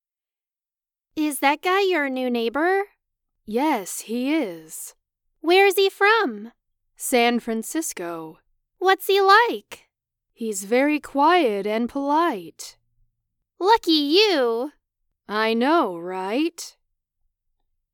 会話の音声
セミナチュラル3
もちろん、これはよくあるソフトウェアで早送りした機械音ではありません。
実際に、アメリカ在住のプロの声優が収録した生の音声です。
Lesson1_Dialogue_SemiNatural3.mp3